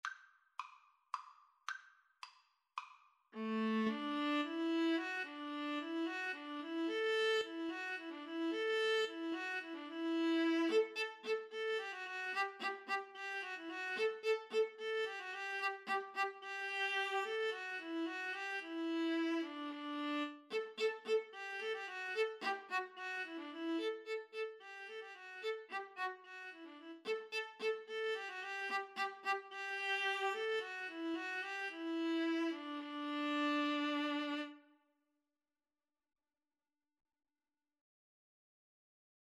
Classical (View more Classical Viola-Cello Duet Music)